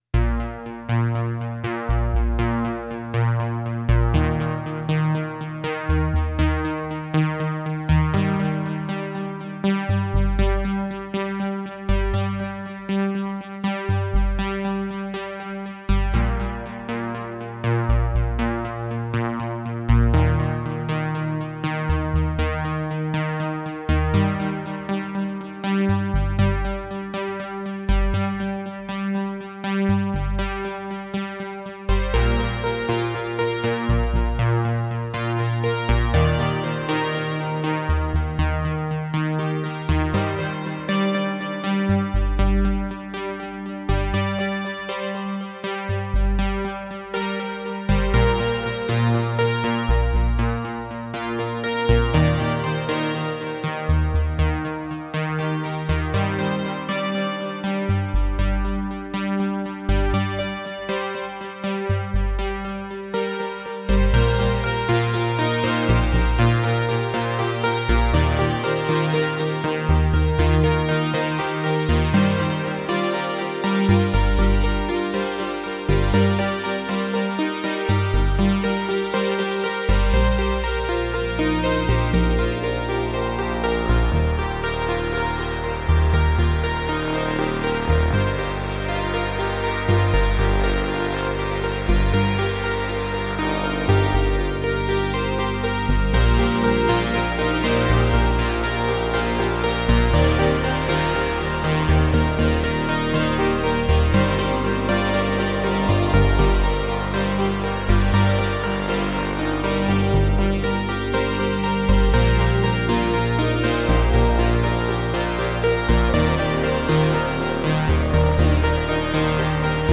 Vintage, eighties, Yamaha.